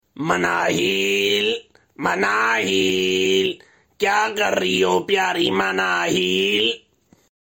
You Just Search Sound Effects And Download.